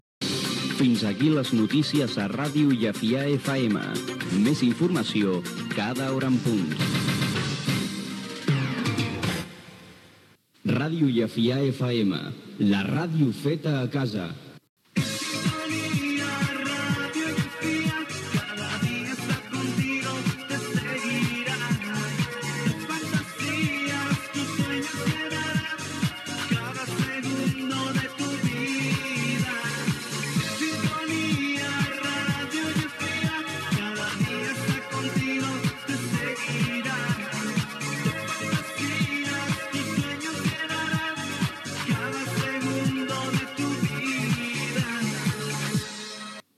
Careta de sortida
identificació de l'emissora i cançó de la ràdio
FM